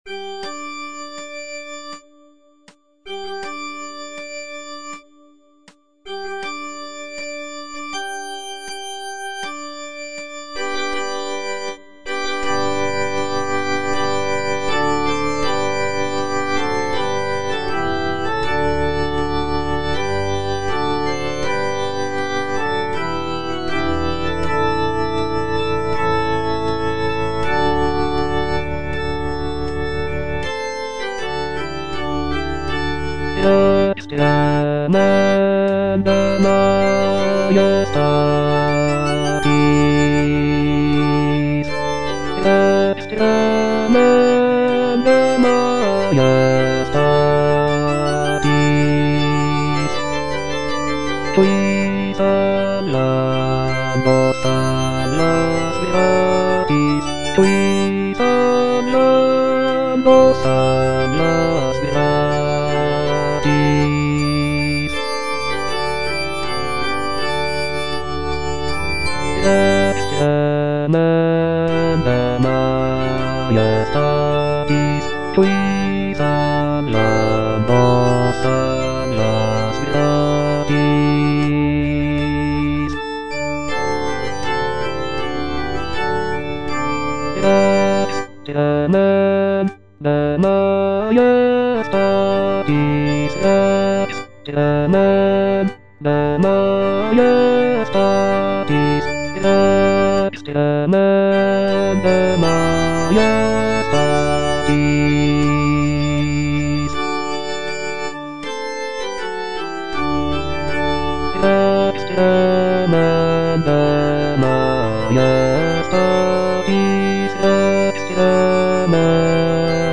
bass II) (Voice with metronome
is a sacred choral work rooted in his Christian faith.